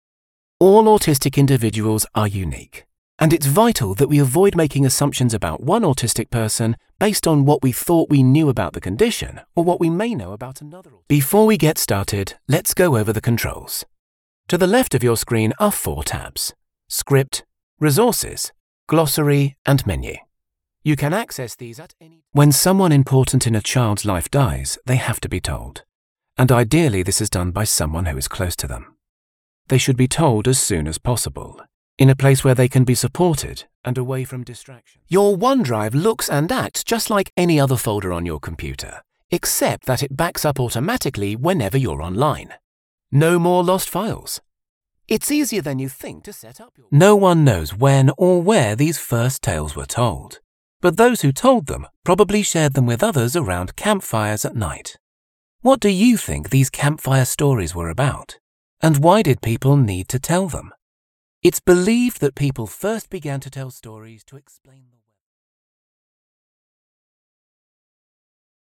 E-learning
Session Booth / Neumann TLM103 / Audient iD4 / MacBook Pro / Adobe Audition
BaritoneBassDeepLow
TrustworthyAuthoritativeWarmConversationalFriendly